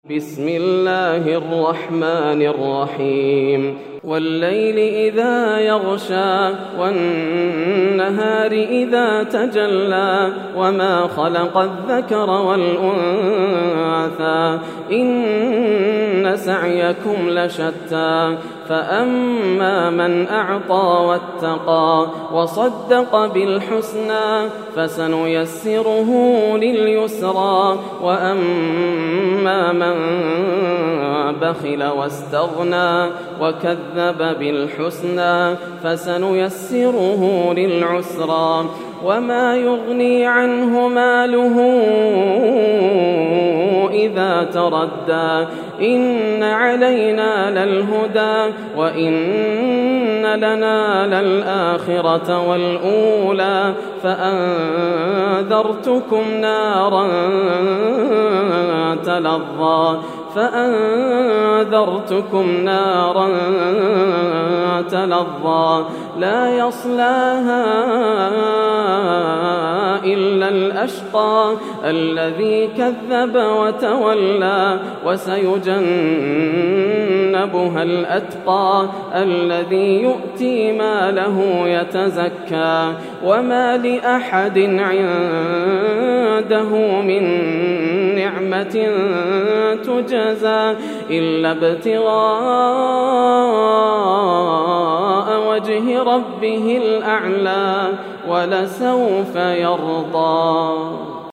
سورة الليل > السور المكتملة > رمضان 1431هـ > التراويح - تلاوات ياسر الدوسري